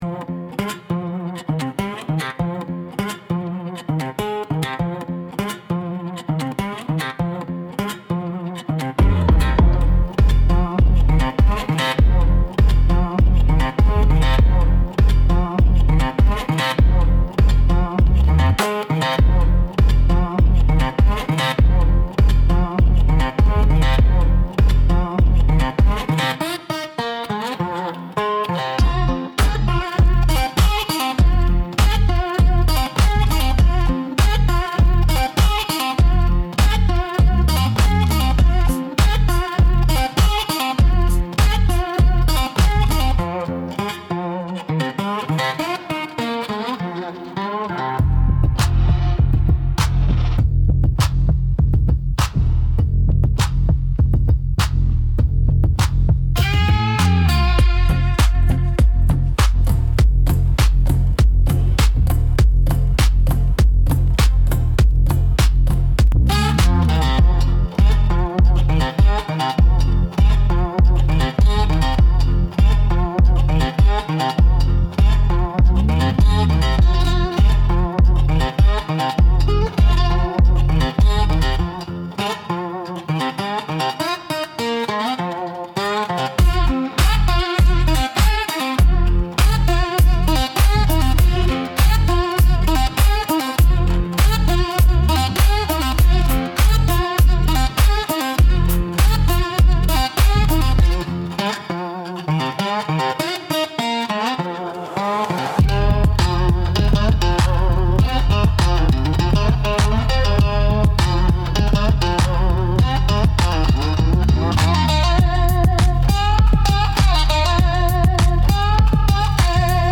Instrumental - Poison in the Reverb